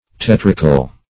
Search Result for " tetrical" : The Collaborative International Dictionary of English v.0.48: Tetric \Tet"ric\, Tetrical \Tet"ri*cal\, a. [L. tetricus, taetricus, from teter, taeter, offensive, foul.]